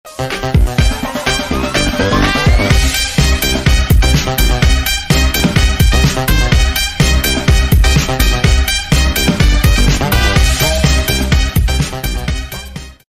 3. Party time эффект
vecherinka-party-time.mp3